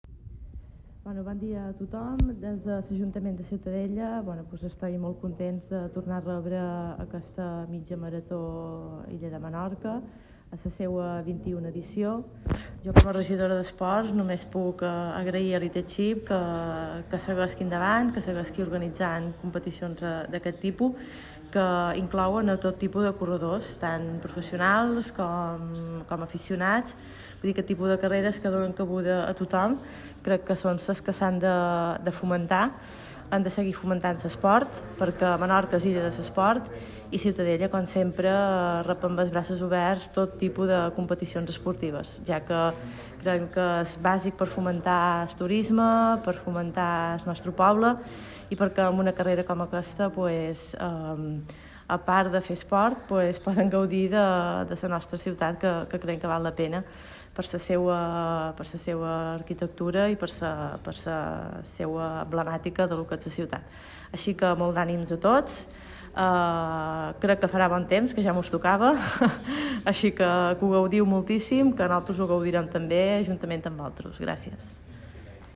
La carrera se ha presentado este miércoles en el mismo ayuntamiento de Ciutadella
Sandra Moll, regidora de Deportes de Ciutadella